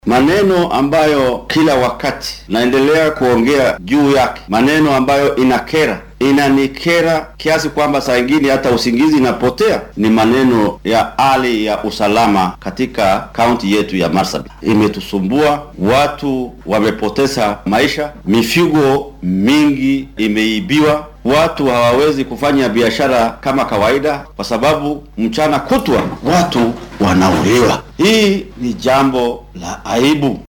Barasaabka-Marsabit.mp3